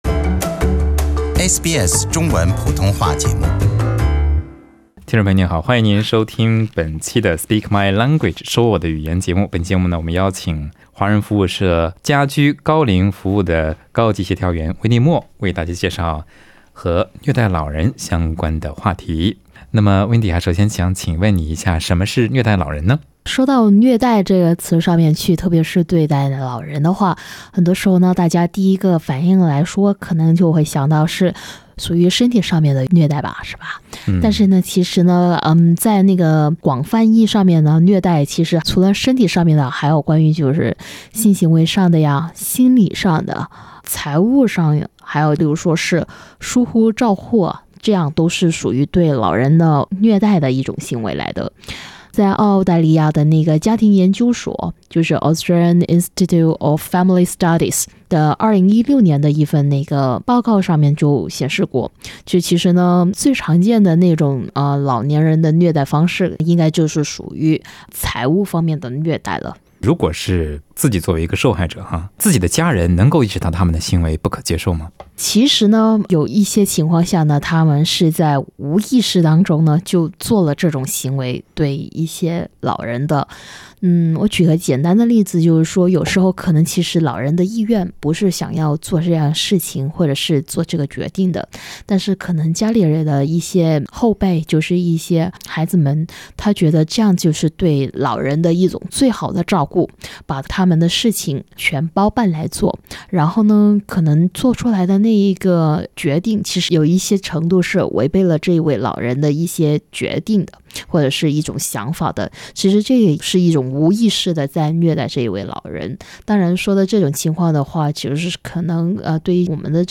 Speak My Language: Conversations about ageing well Source: Ethnic Communities Council NSW